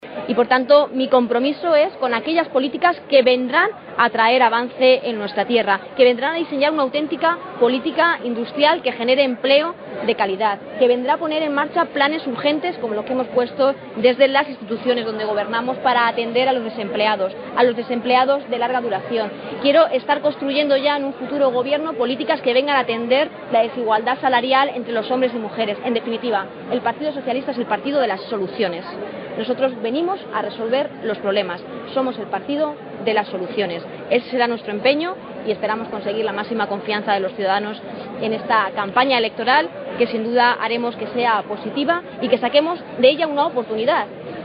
Acto público en Puertollano
Cortes de audio de la rueda de prensa